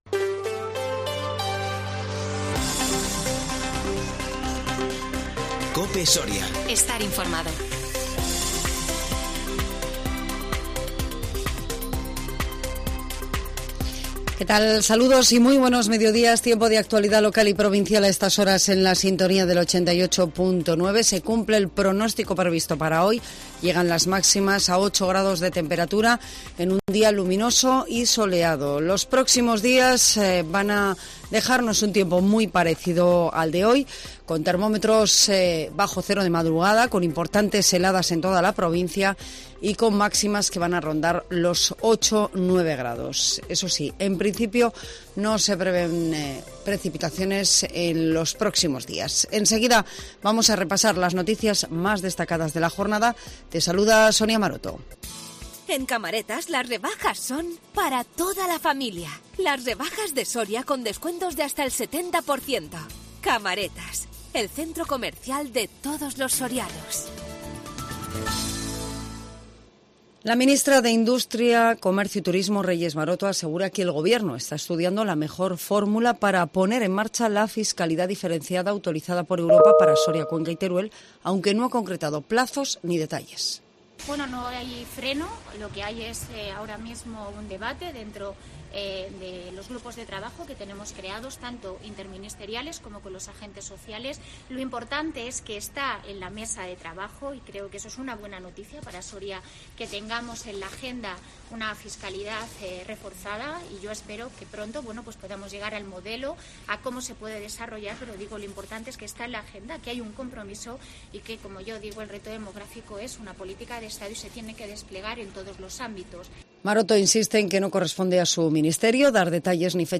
INFORMATIVO MEDIODÍA 14 ENERO 2022